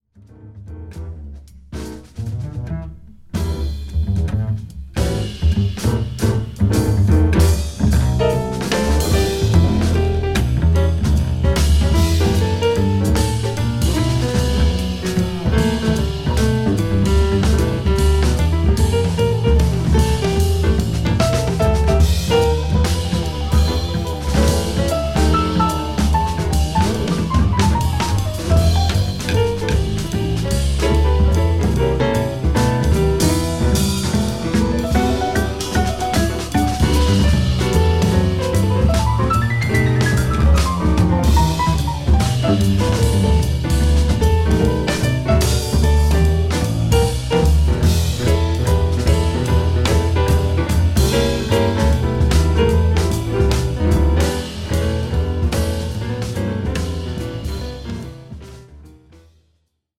日本のジャズ・ベーシスト、初リーダー作。